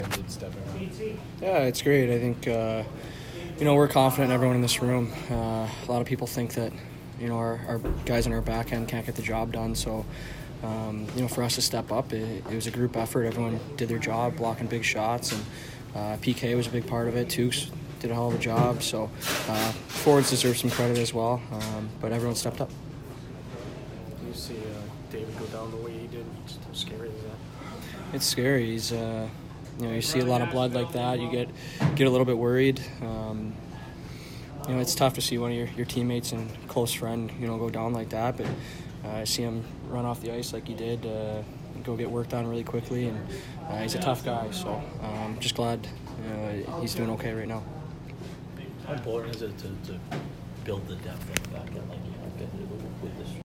Torey Krug post-game 3/17